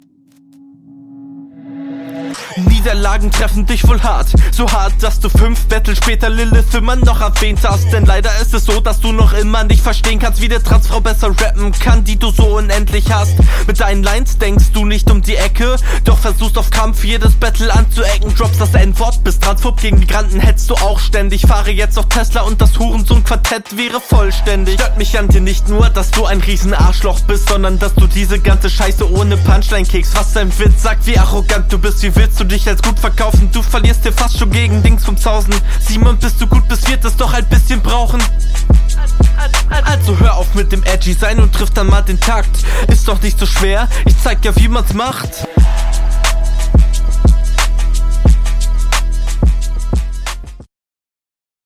Interessant gereimt teilweise.